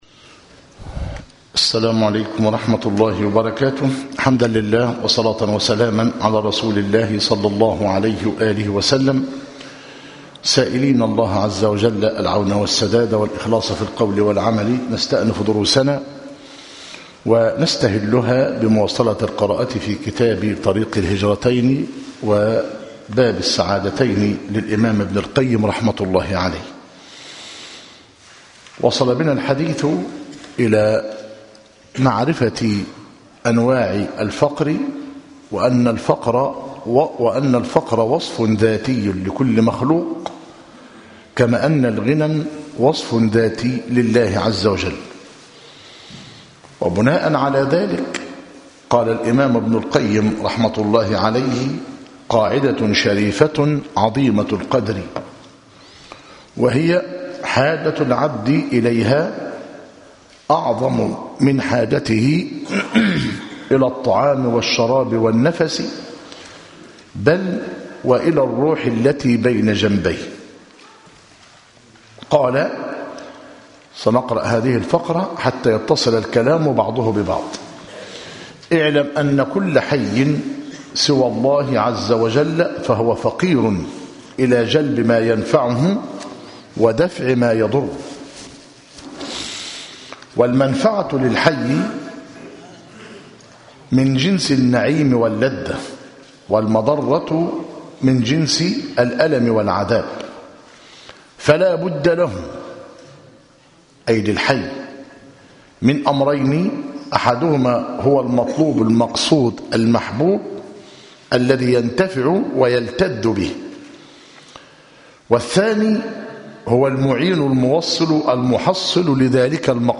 كتاب طريق الهجرتين وباب السعادتين للإمام ابن القيم رحمه الله - مسجد التوحيد - ميت الرخا - زفتى - غربية - المحاضرة الرابعة عشر - بتاريخ 13 - ربيع آخر- 1437هـ الموافق 23 - يناير- 2016 م